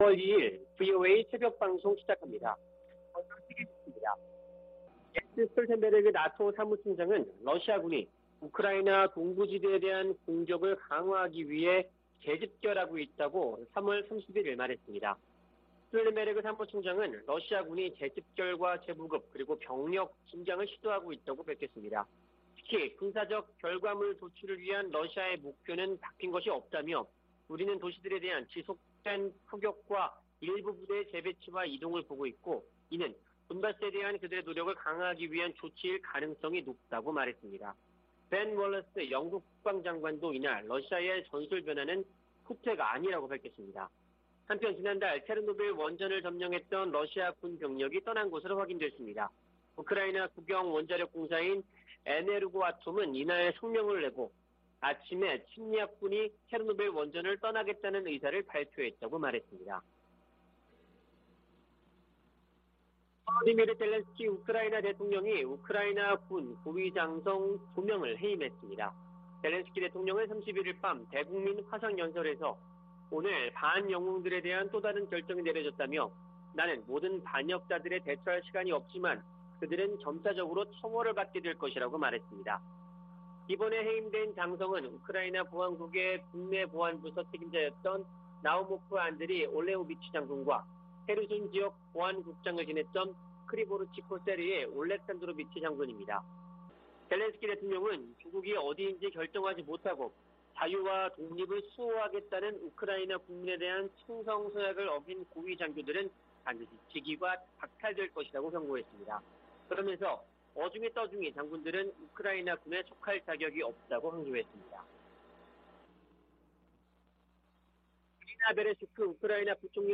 VOA 한국어 '출발 뉴스 쇼', 2022년 4월 2일 방송입니다. 북한이 ICBM 발사에 이어 조기에 핵실험 도발에 나설 것이라는 전망이 나오고 있습니다. 미 국무부는 북한의 추가 도발 가능성을 주시하고 있다면서 추가 압박을 가하는 등 모든 일을 하고 있다고 강조했습니다.